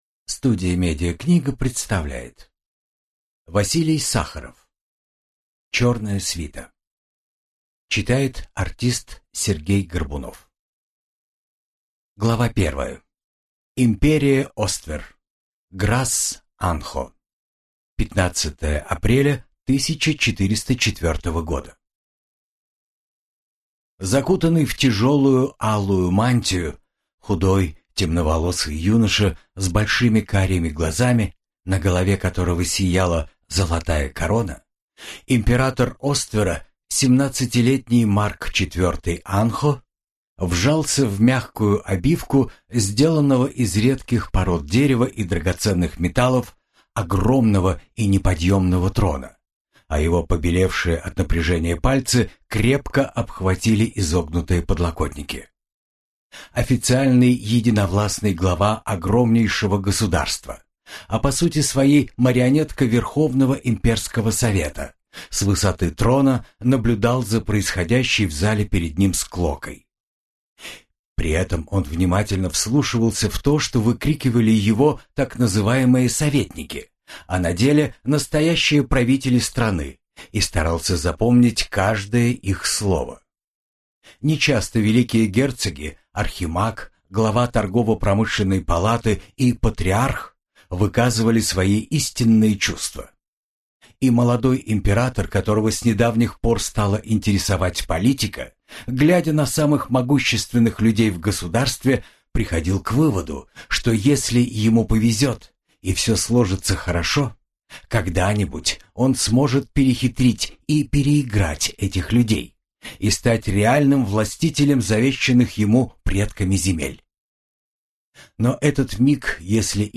Аудиокнига Черная свита | Библиотека аудиокниг